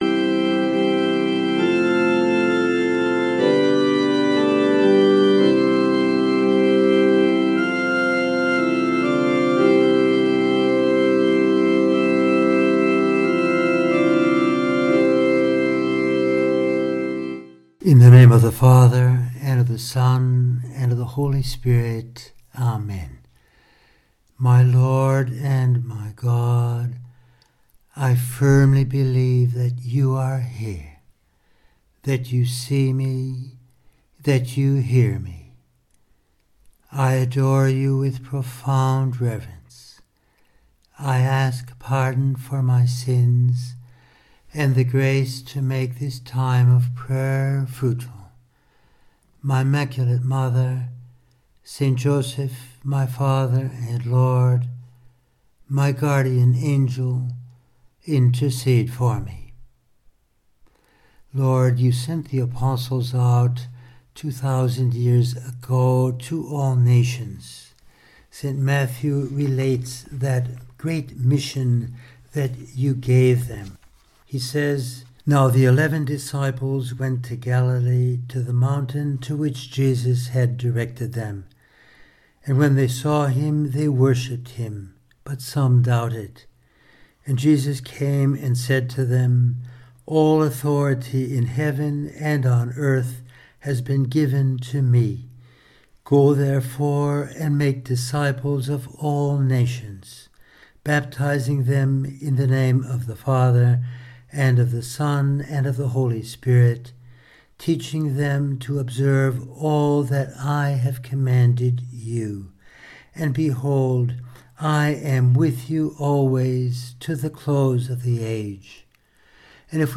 For this reason we need the virtue of fortitude, which strengthens the will. In this meditation we consider: